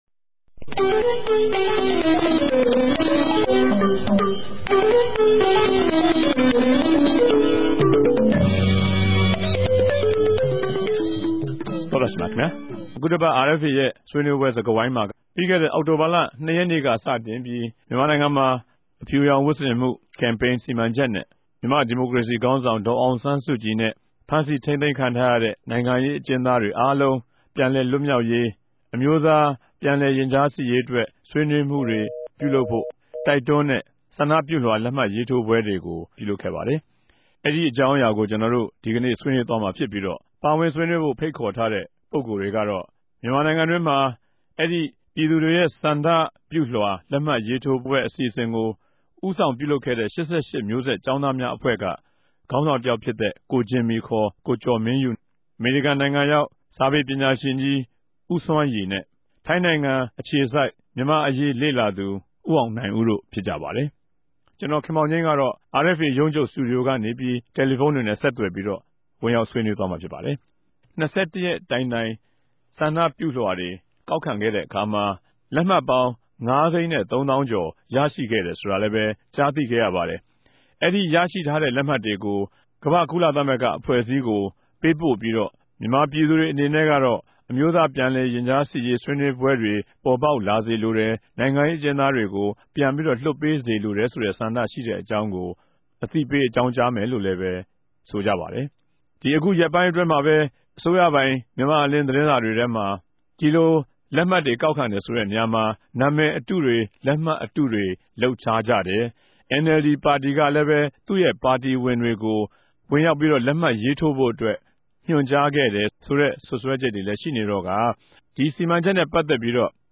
ဝၝရြင်တန်္ဘမိြႚတော် RFAစတူဒီယိုထဲကနေ
တယ်လီဖုန်းနဲႛ ဆက်သြယ် မေးူမန်း္ဘပီး တင်ဆက်ထားတာကို နားထောငိံိုင်ပၝတယ်။